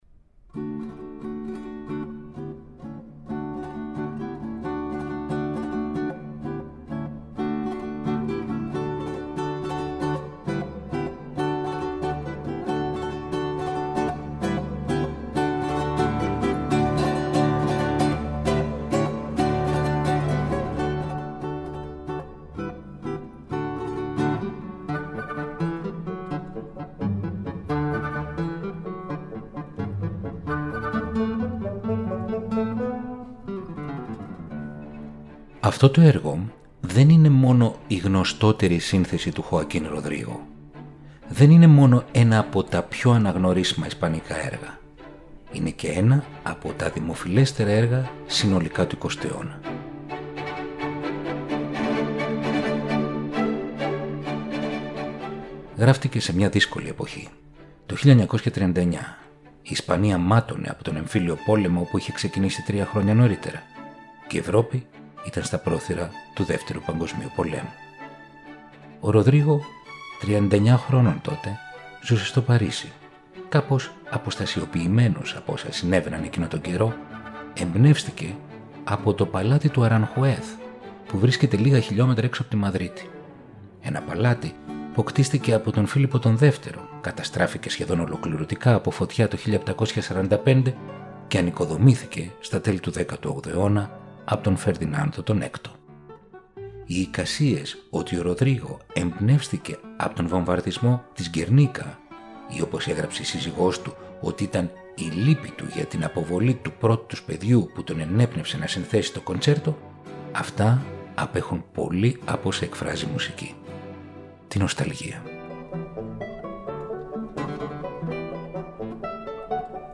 Η τζαζ συναντά την κλασσική μουσική